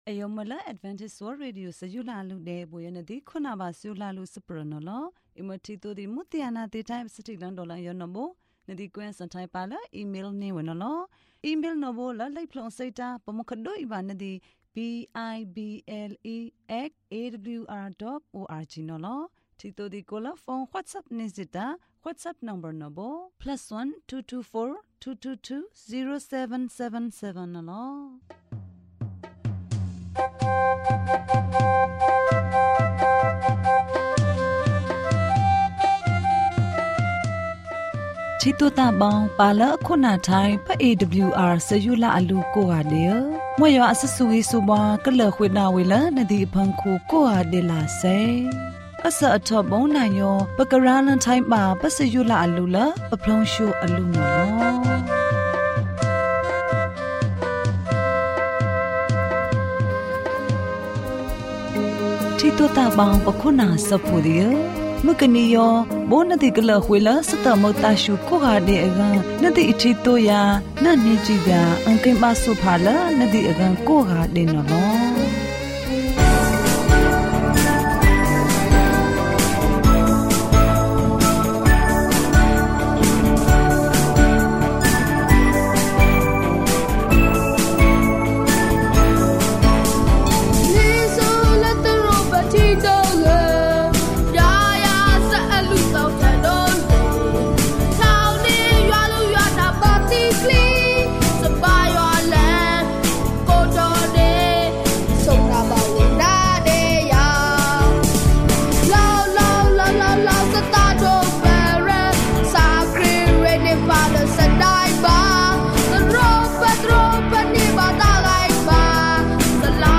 ပုံပြင်။ဓမ္မသီချင်း။တရားဒေသနာ။